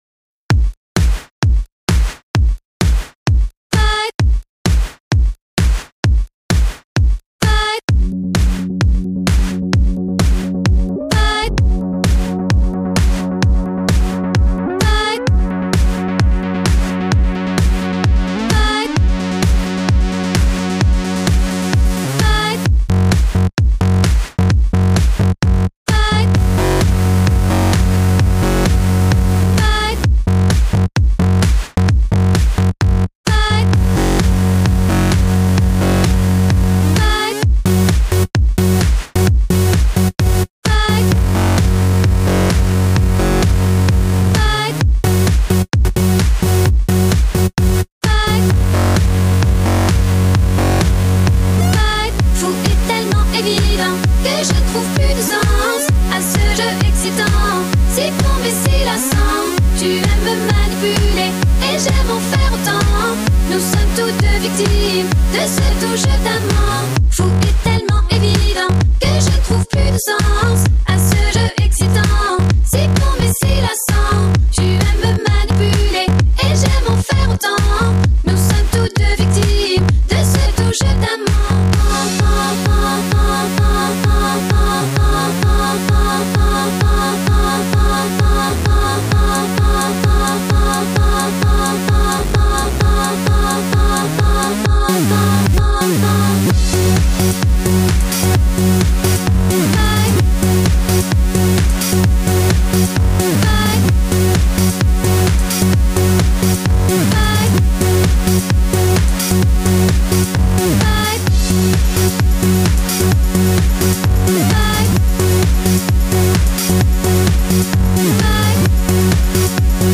qui resa decisamente più marziale e diretta.